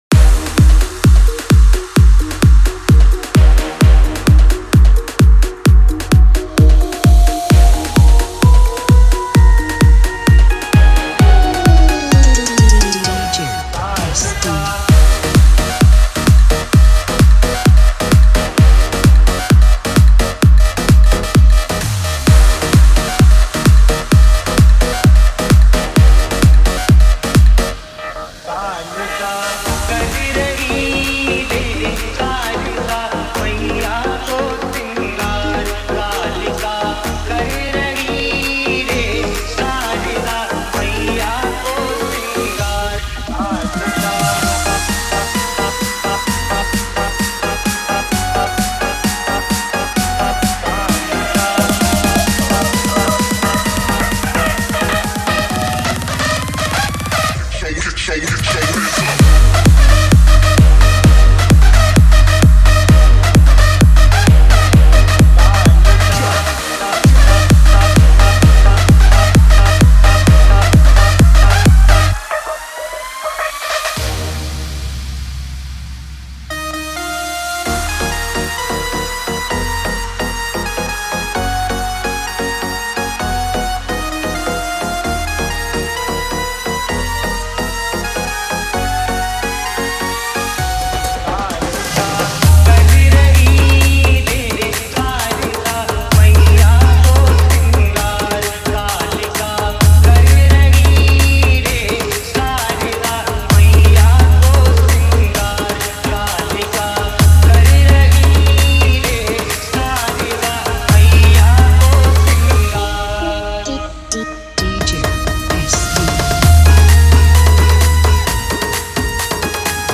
Bhakti DJ Remix Songs